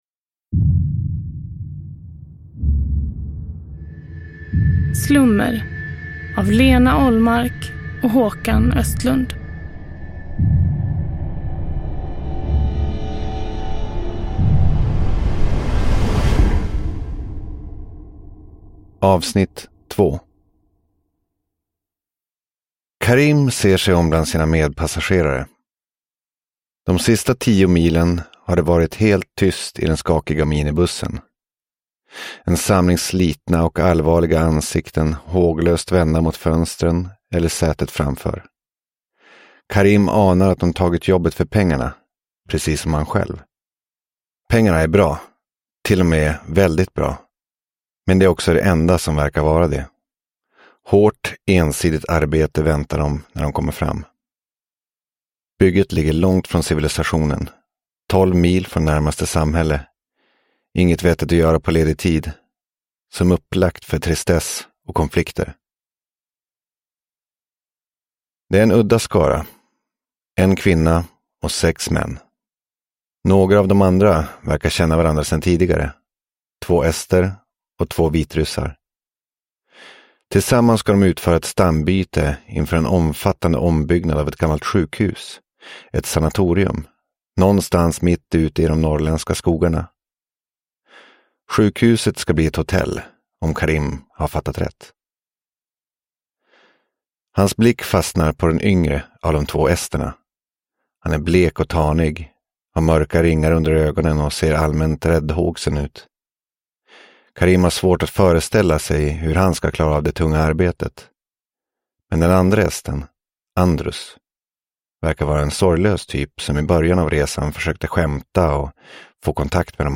Slummer - Del 2 – Ljudbok – Laddas ner